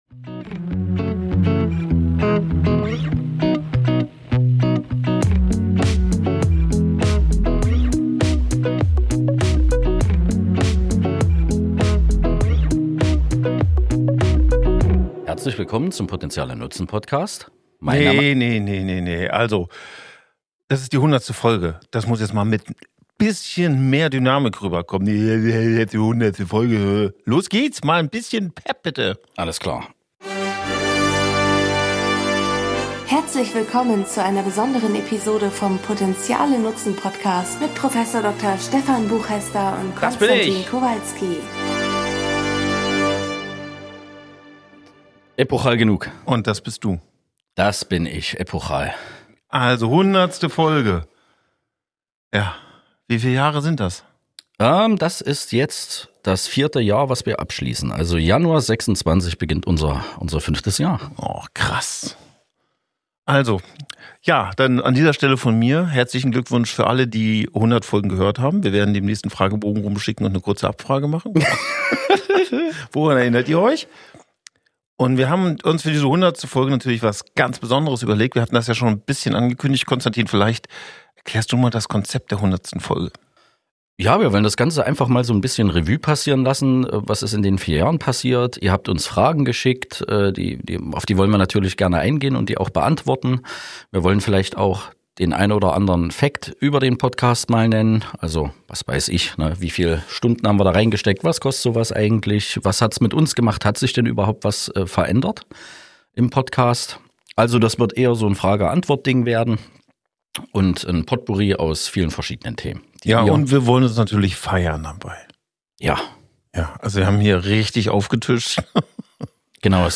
Die Folge lebt vom direkten Dialog, einem ehrlichen Blick hinter die Kulissen, überraschenden Fakten sowie emotionalen und witzigen Momenten. Besonders wertvoll ist die Beantwortung vieler Hörerfragen: zu Noten, innerem Schweinehund, Prüfungsangst, Selbstbild, kindlicher Erinnerung, Hundeliebe, Mindset, Unterschied zwischen Hobby und Beruf, Kontakt zu Gefühlen und vielem mehr.